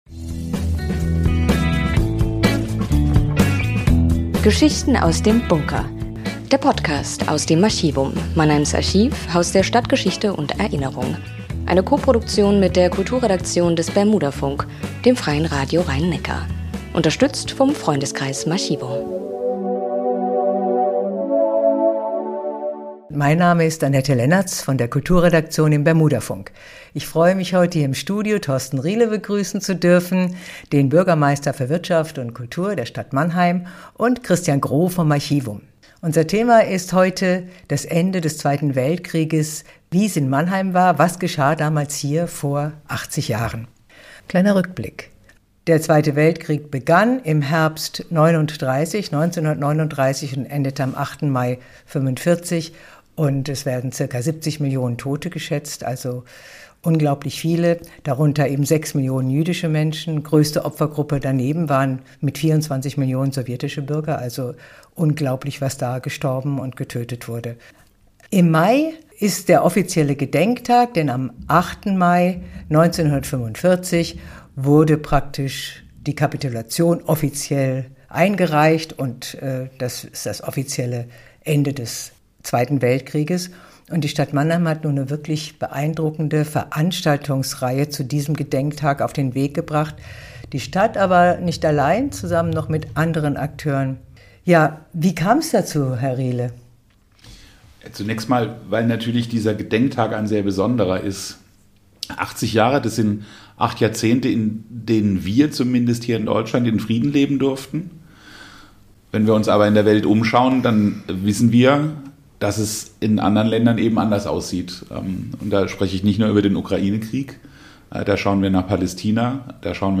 Über die Ereignisse im März 1945 und über die seit Kriegsende umfangreichste Veranstaltungsreihe berichten Bürgermeister Thorsten Riehle